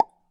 message.wav